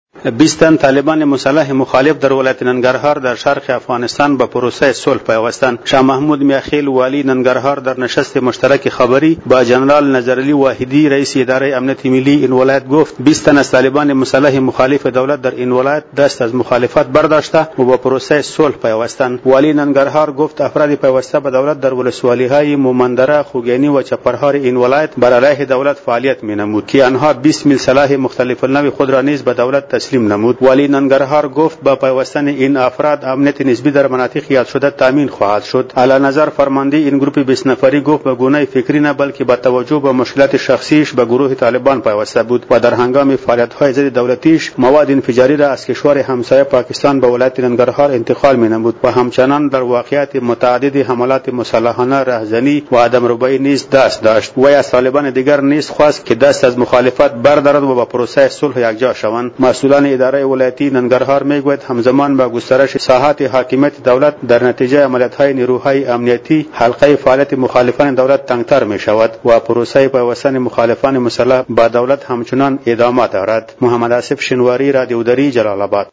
جزئیات در گزارش خبرنگار رادیودری: